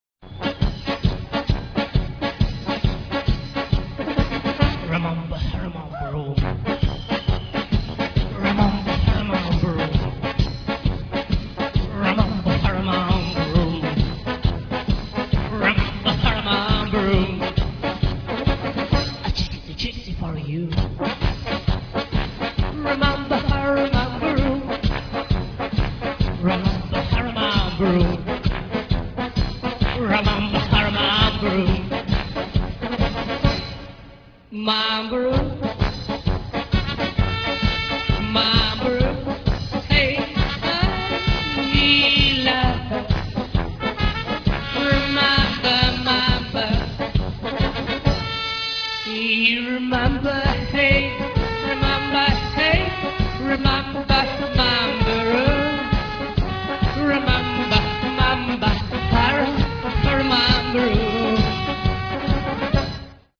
Первый концерт на Шаболовке (1993)